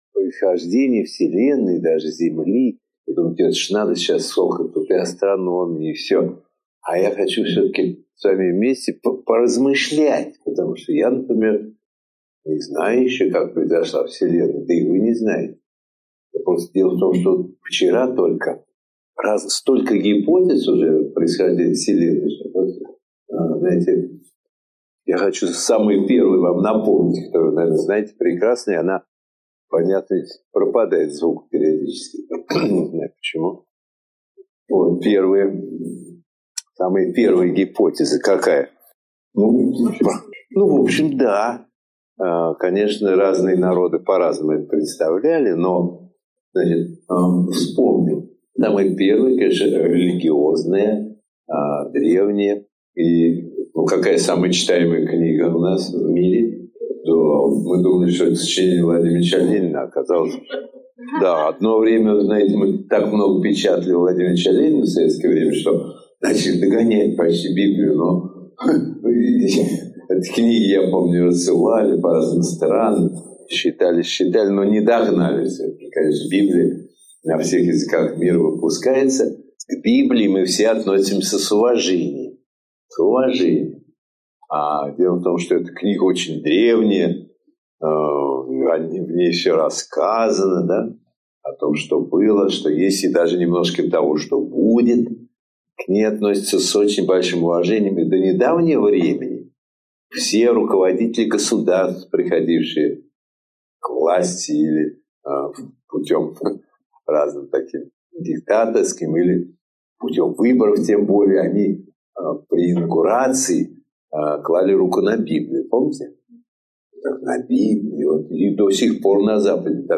Аудиокнига Происхождение Вселенной и Земли. Эволюция жизни | Библиотека аудиокниг
Эволюция жизни Автор Николай Дроздов Читает аудиокнигу Николай Дроздов.